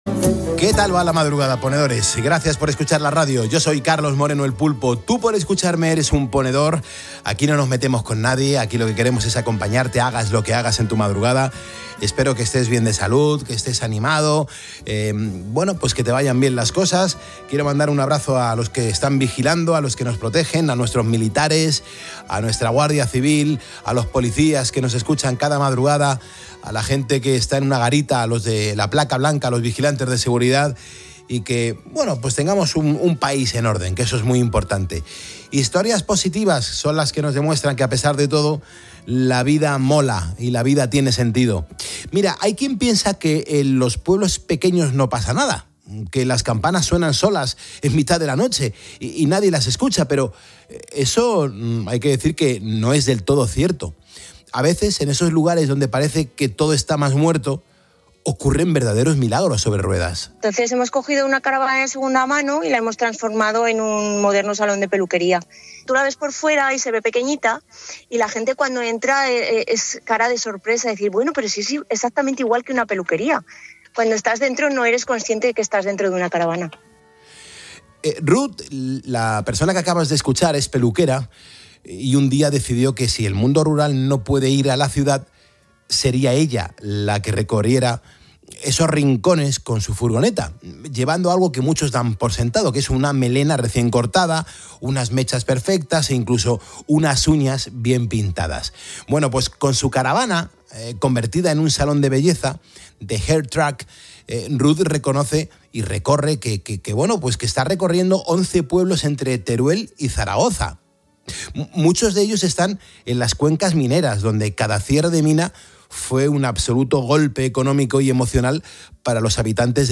Ambas profesionales han compartido su historia en COPE.